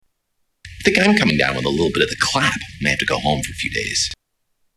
Clap